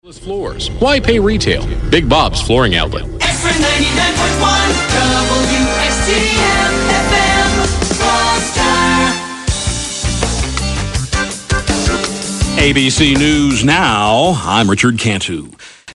WXGM-FM Top of the Hour Audio: